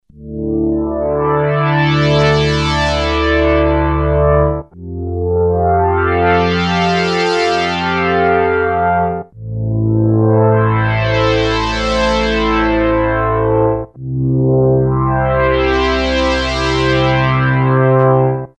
No external effects added
Enveloped hardware filter is smooth (12 bit control voltage). A slow envelope to show that there are not audible "steps":
Polyphonic. Slow filter envelope is smooth (290kB)